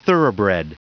Prononciation du mot thoroughbred en anglais (fichier audio)
Prononciation du mot : thoroughbred